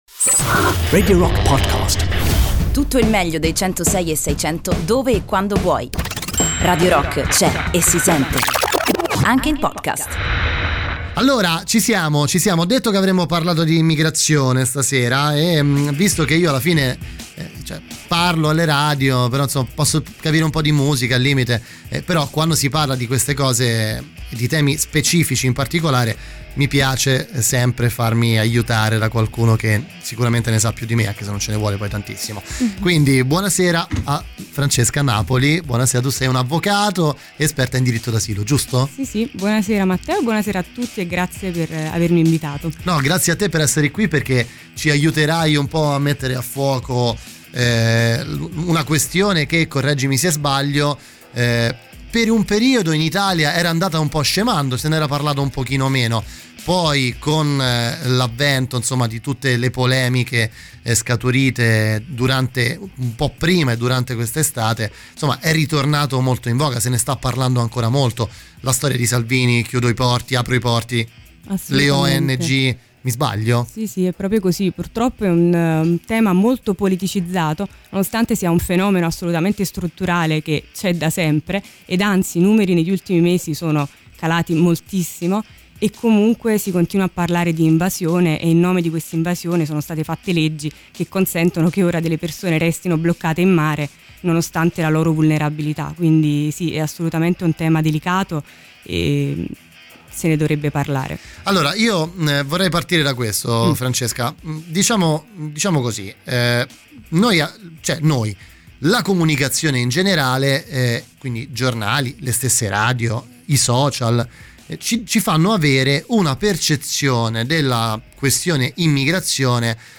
"Intervista"